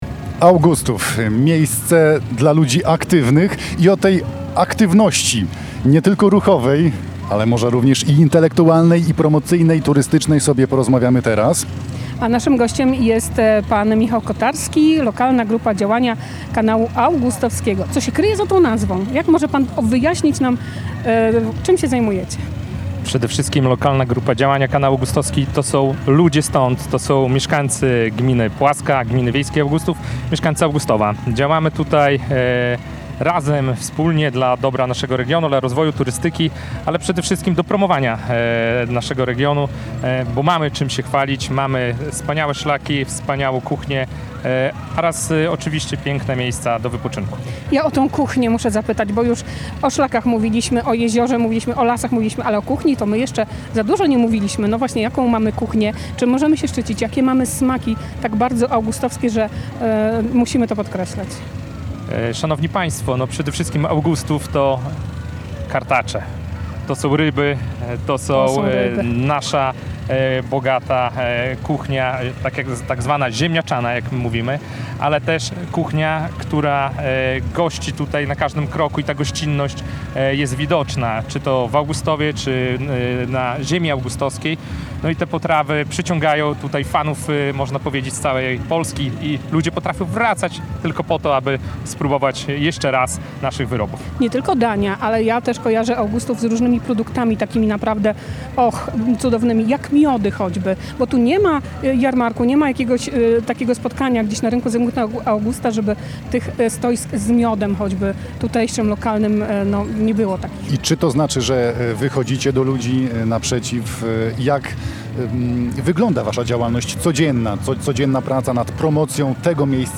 W piątek (2.05) Polskie Radio Białystok nadawało na żywo z serca letniej stolicy Polski, czyli Augustowa.
Rozmowa